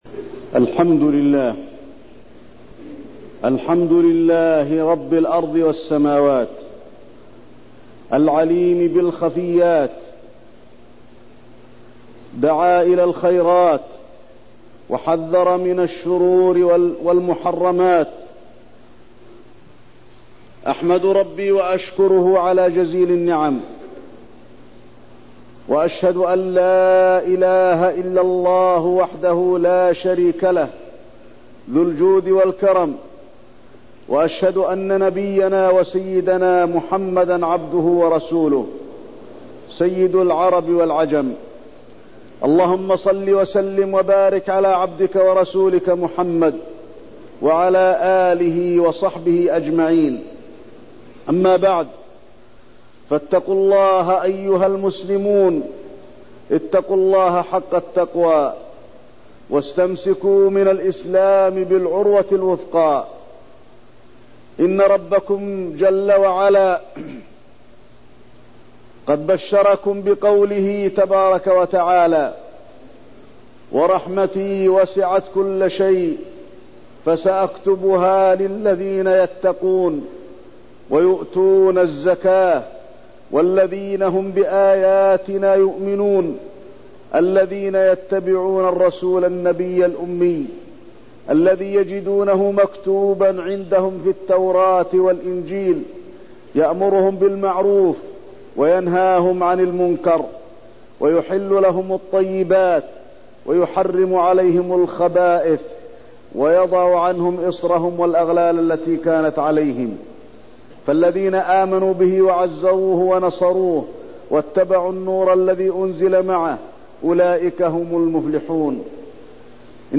تاريخ النشر ٥ رمضان ١٤١٣ هـ المكان: المسجد النبوي الشيخ: فضيلة الشيخ د. علي بن عبدالرحمن الحذيفي فضيلة الشيخ د. علي بن عبدالرحمن الحذيفي الحث على الطاعة في رمضان